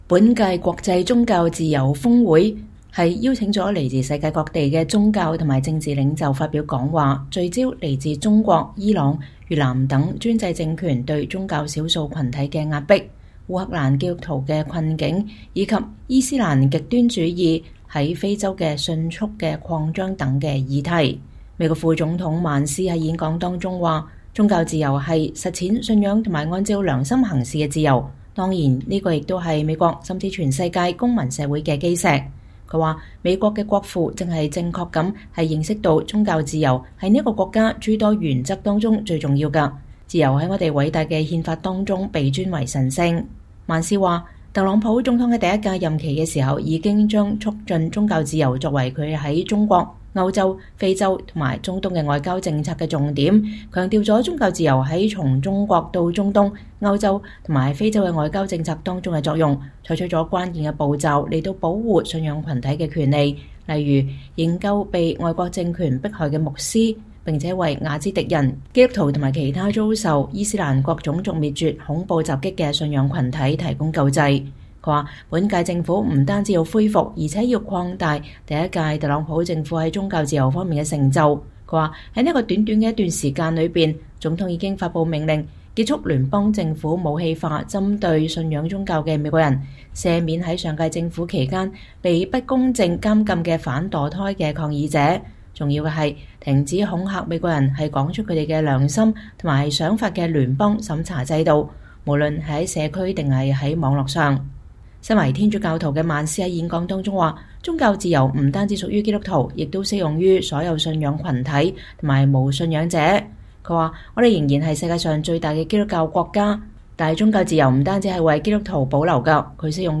美國副總統J.D.萬斯(JD Vance)星期三(2月5日)在華盛頓舉辦的2025年度 “國際宗教自由峰會” (IRF Summit)上發表演講時表示，特朗普政府將在國內外繼續捍衛宗教自由，不僅在 “恢復，而且在擴大” 美國的宗教自由。
當萬斯在總統唐納德·特朗普(Donald Trump)競選主題曲“美國優先” 的伴奏下，走進華盛頓希爾頓酒店一間爆滿的宴會廳，台下有許多來自世界各地的信仰團體、政府官員和人權活動人士起身鼓掌, 經久不息。